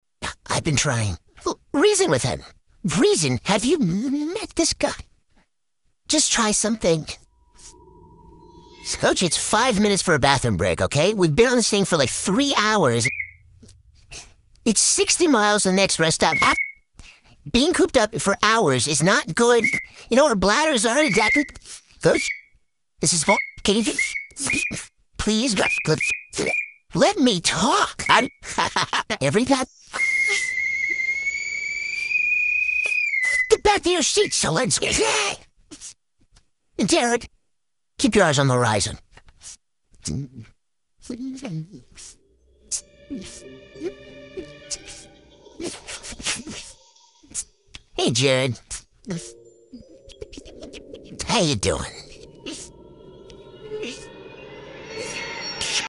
just wanted to try the new voice filter